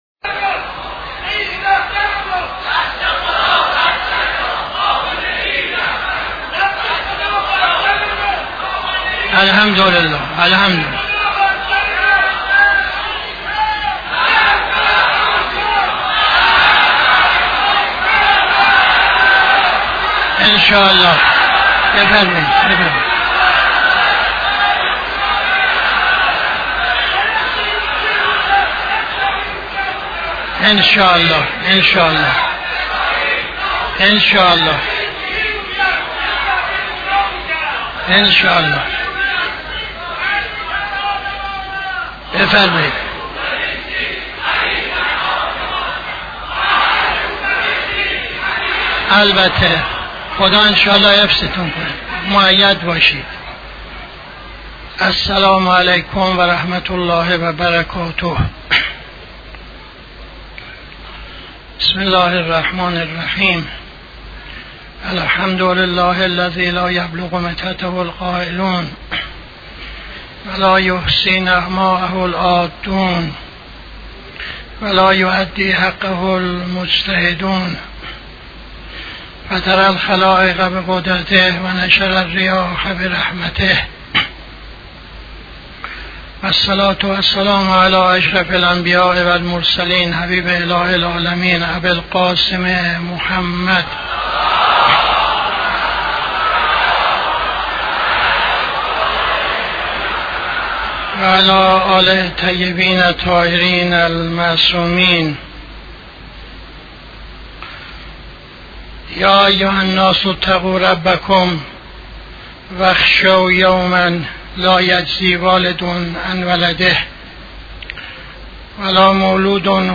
خطبه اول نماز جمعه 05-11-80